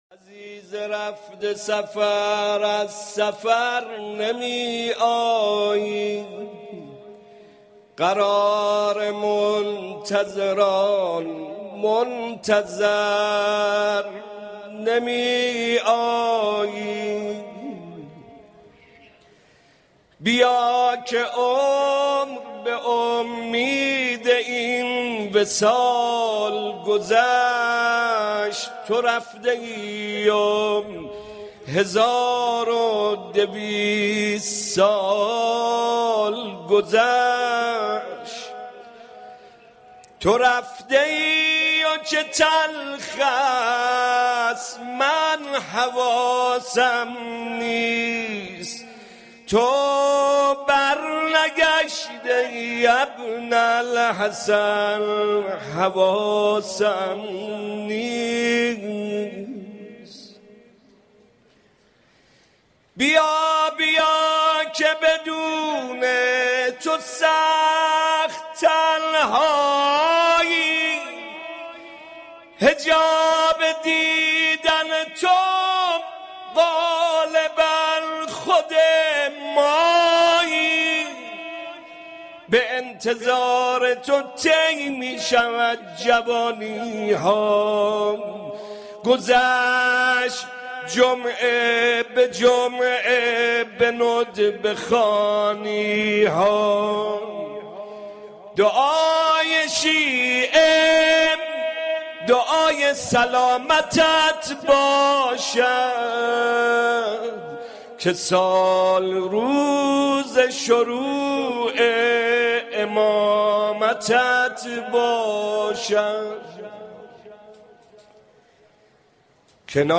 عید بیعت با امام زمان علیه السلام - مدح - عزیز رفته سفر از سفر نمی ایی